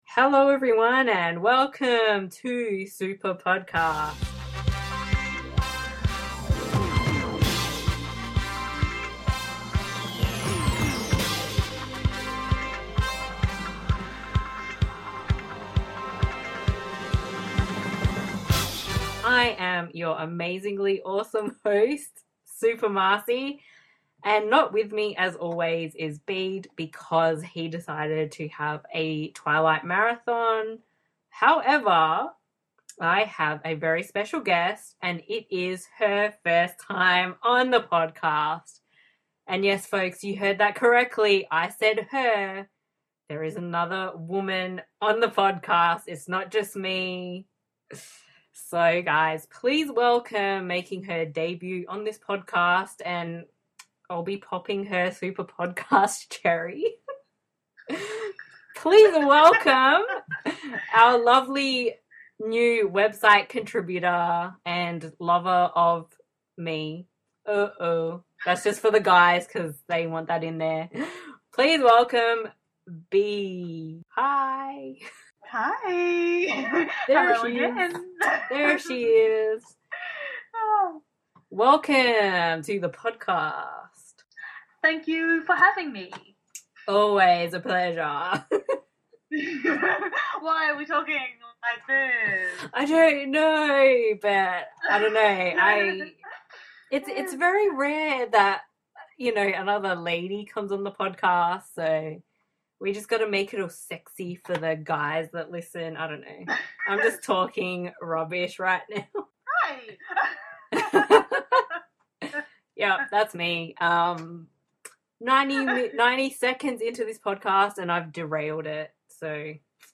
yes you can listen to two women ... whoa!